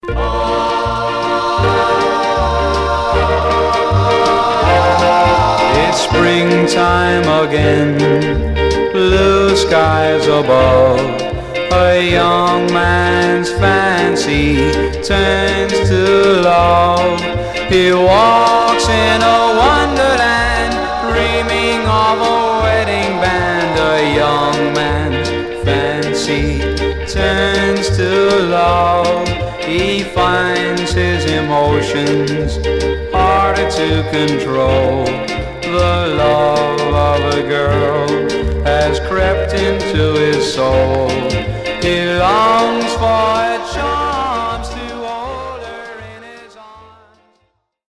Stereo/mono Mono